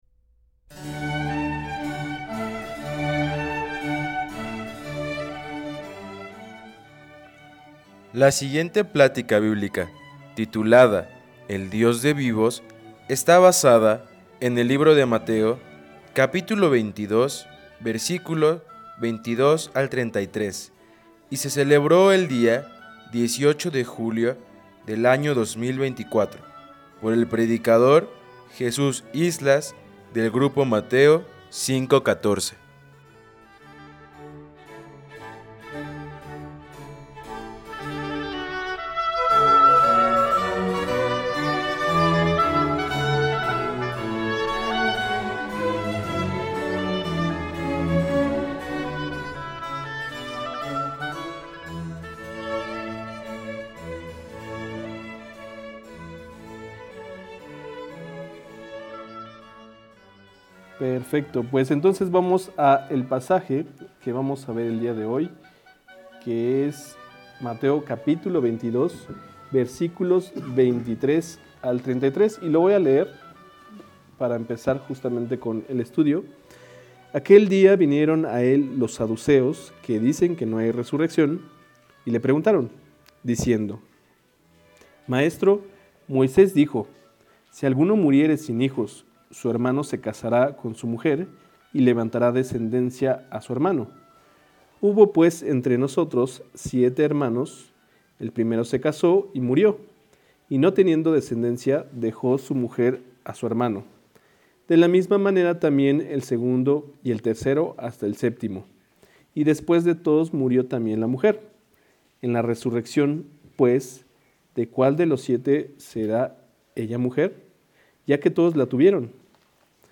2024 El Dios de Vivos Preacher